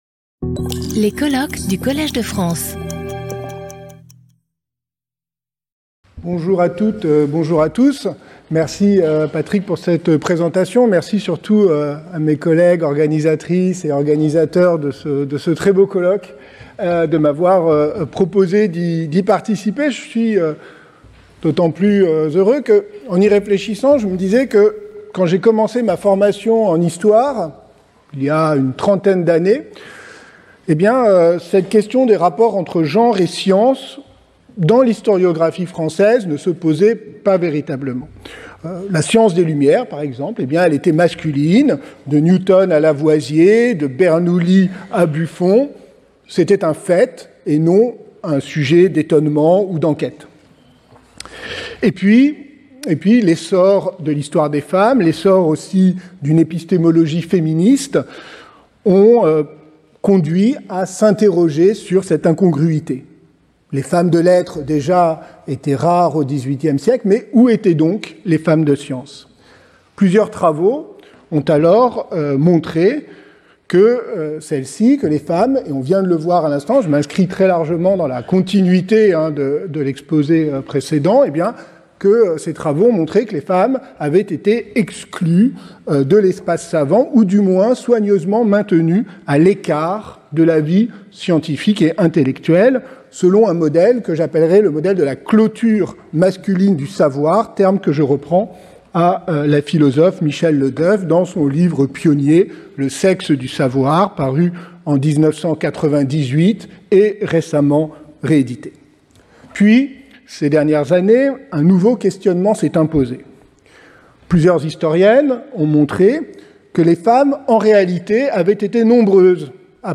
Each 30-minute paper is followed by a 10-minute discussion.